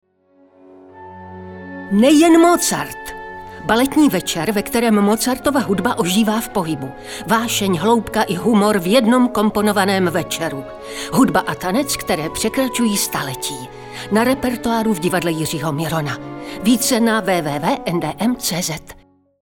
Oficiální audiospot NDM